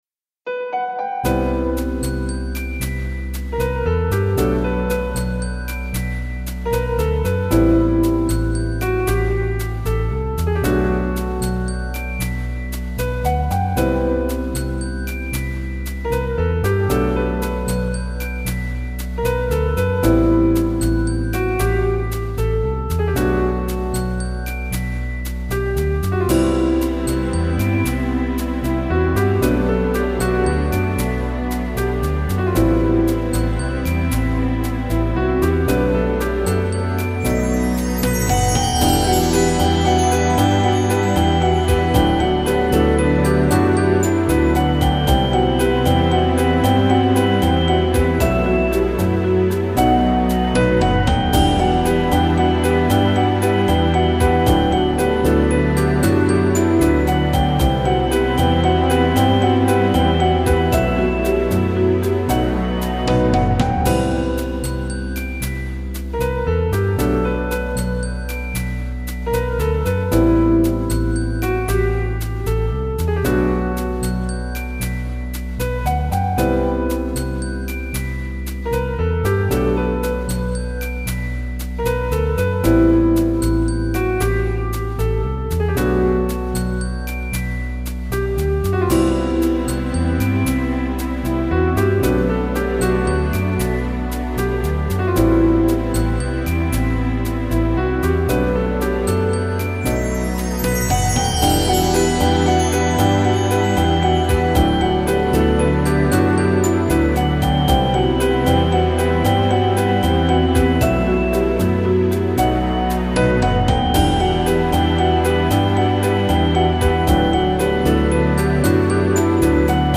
2017.02 | 大人な雰囲気 | ジャズ | 2分54秒/2.65 MB